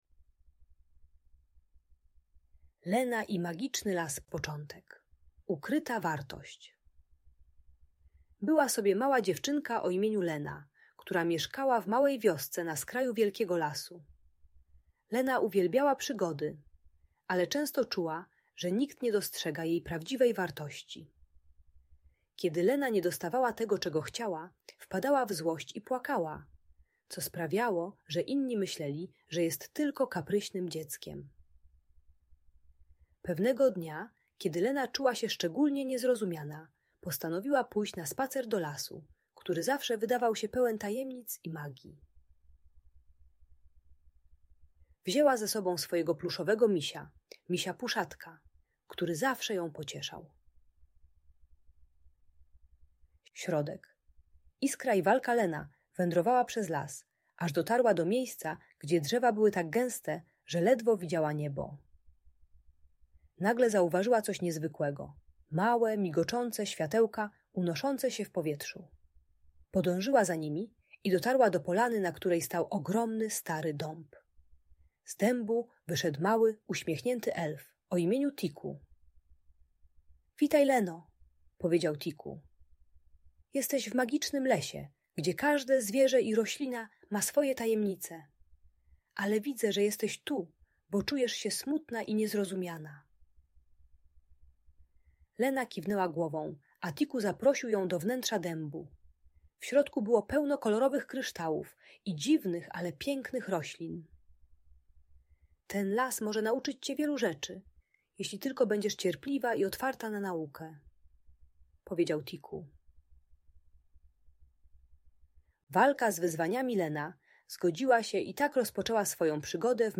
Lena i Magiczny Las - story o cierpliwości i odkrywaniu wartości - Audiobajka